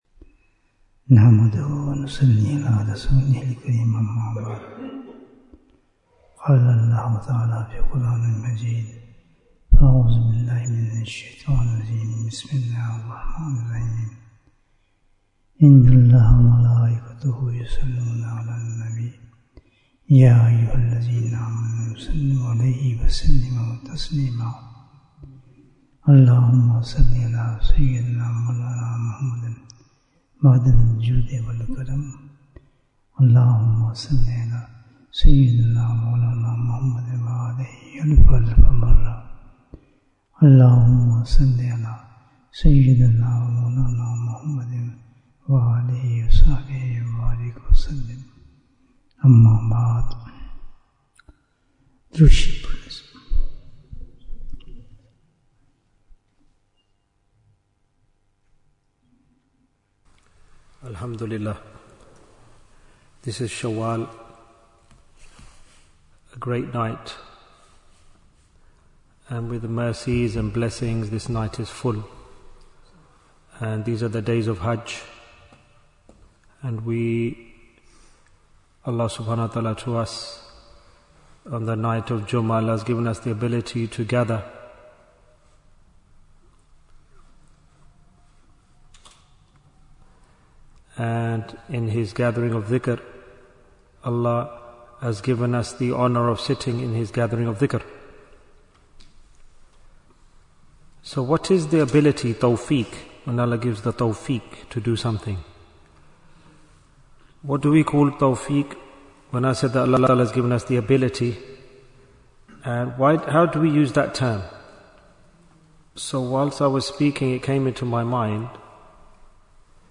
How Can We Become Sabir & Shakir? Bayan, 58 minutes2nd April, 2026